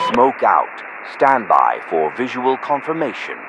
Radio-jtacSmokeOK2.ogg